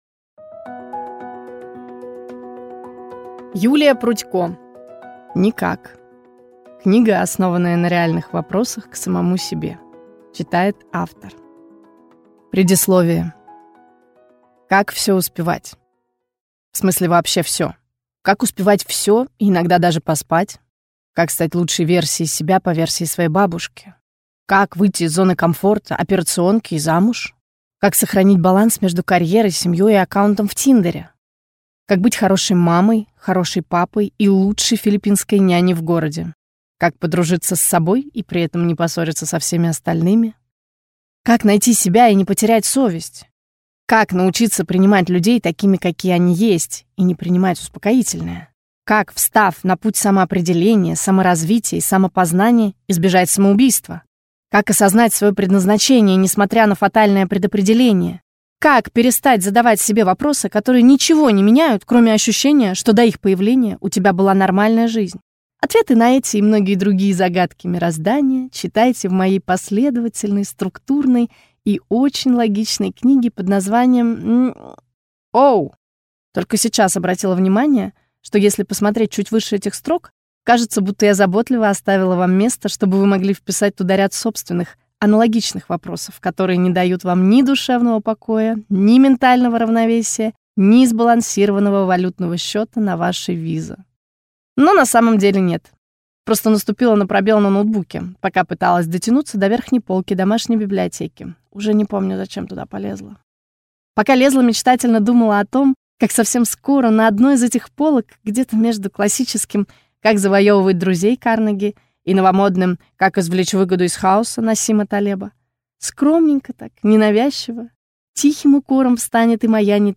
Аудиокнига Никак | Библиотека аудиокниг